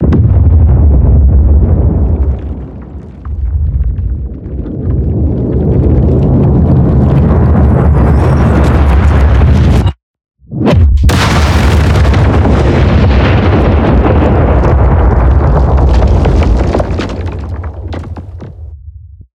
shatterpoint_blowout.ogg.bak